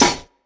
• Snare Single Hit B Key 125.wav
Royality free steel snare drum tuned to the B note. Loudest frequency: 3075Hz
snare-single-hit-b-key-125-ntA.wav